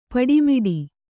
♪ paḍi miḍi